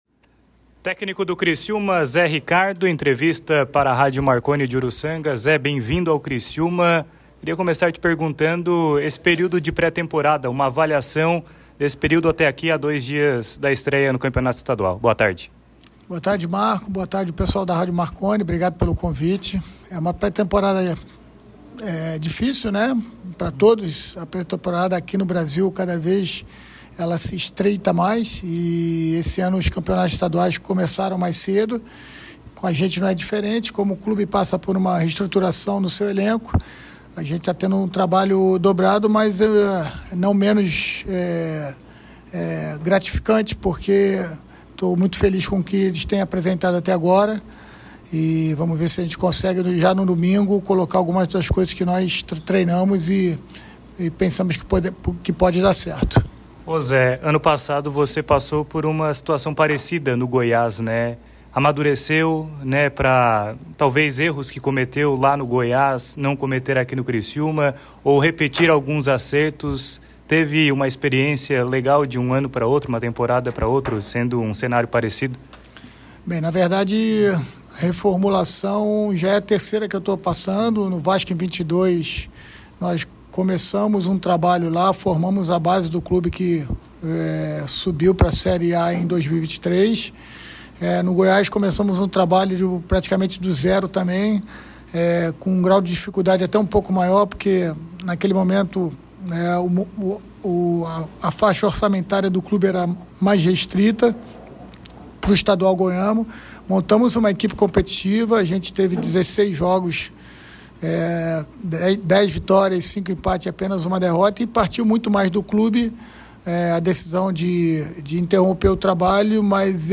Ouça e leia as declarações do treinador do Criciúma em entrevista à Rádio Marconi nesta sexta-feira, 17